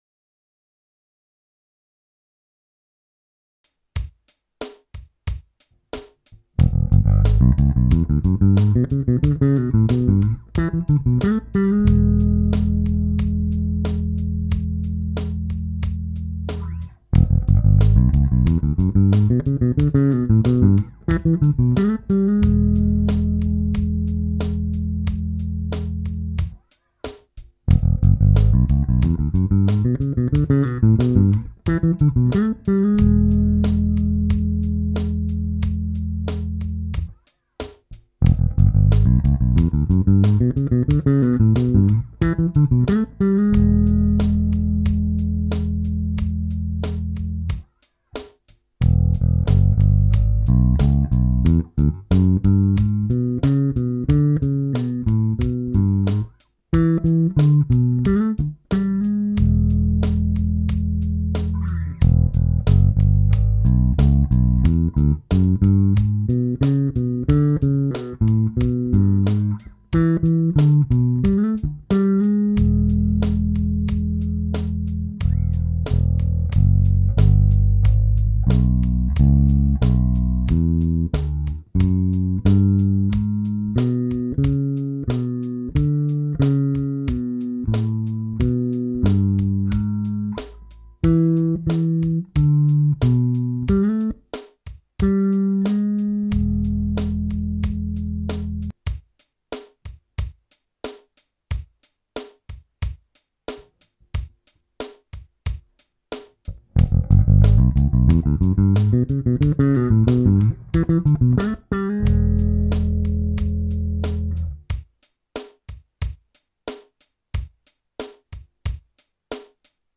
L213 E7 fast bluesy bass run